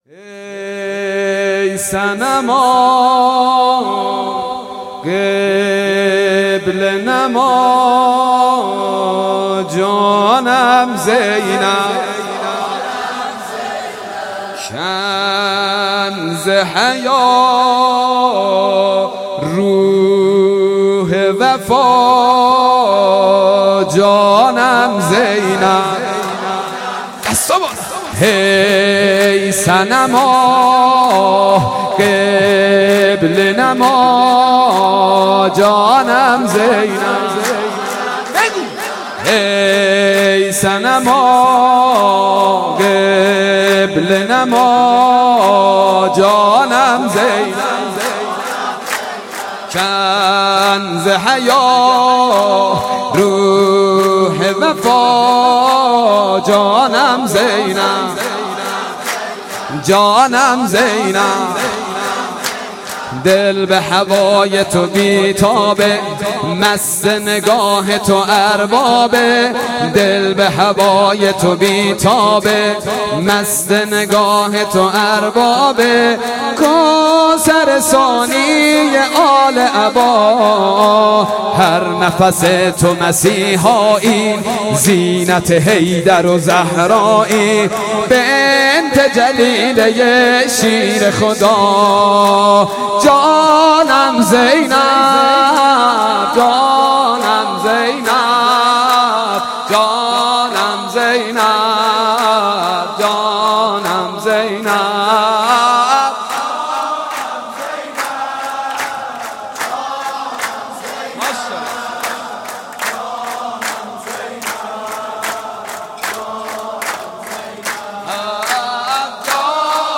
حسینیه بیت النبی
ولادت حضرت زینب س